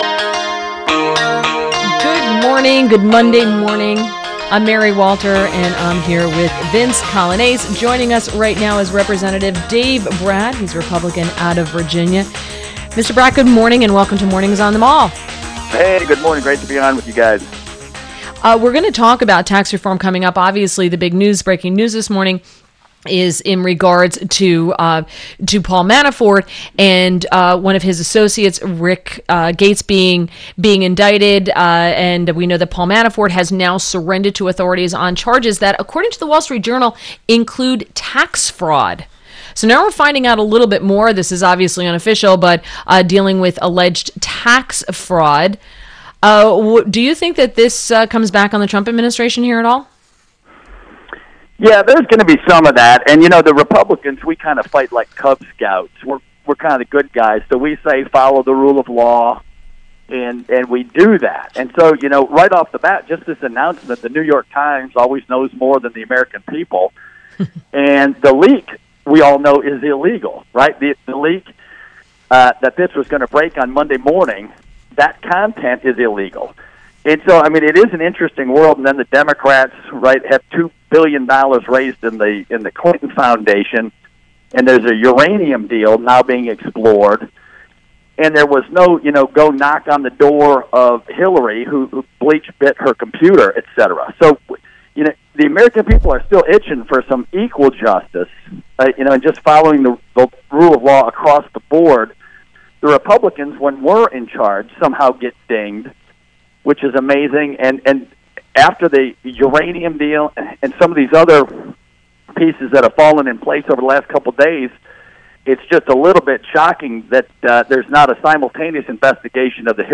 WMAL Interview - REP. DAVE BRAT - 10.30.17